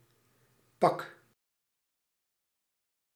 Ääntäminen
UK : IPA : /s(j)uːt/